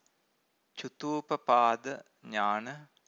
cutupapada-nana.mp3